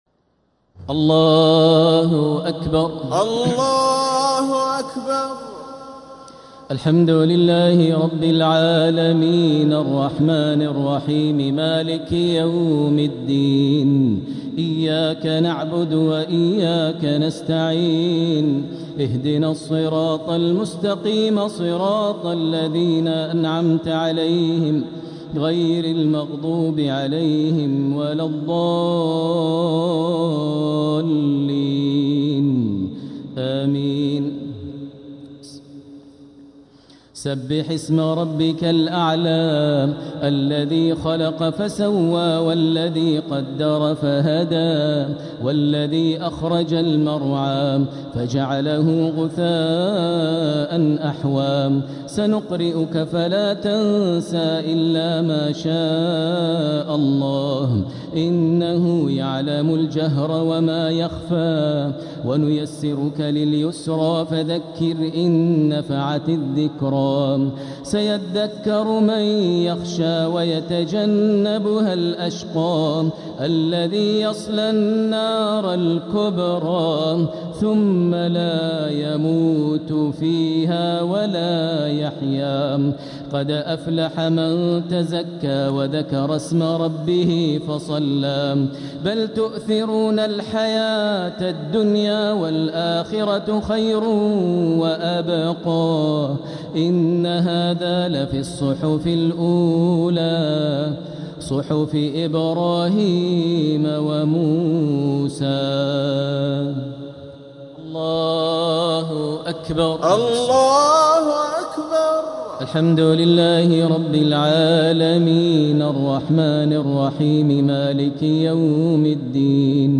الشفع و الوتر ليلة 15 رمضان 1447هـ | Witr 15th night Ramadan 1447H > تراويح الحرم المكي عام 1447 🕋 > التراويح - تلاوات الحرمين